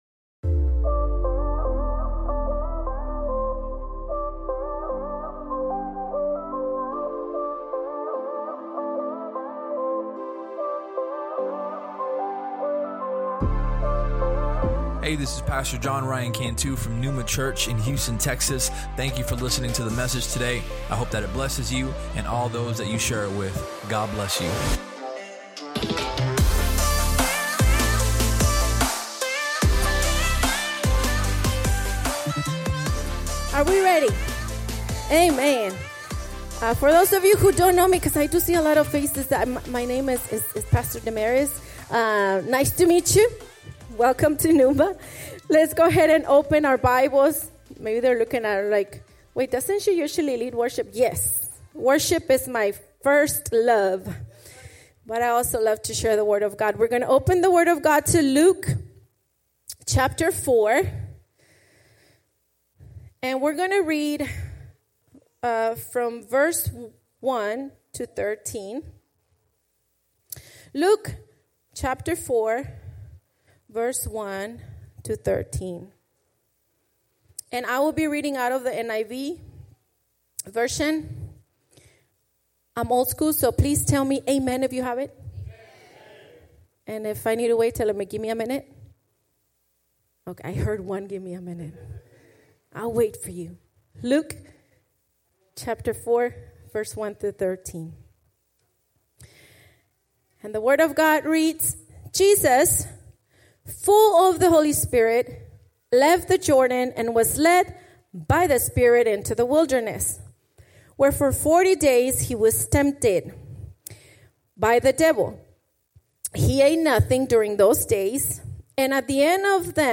Sermon Topics: Temptation, Scripture, Preparation If you enjoyed the podcast, please subscribe and share it with your friends on social media.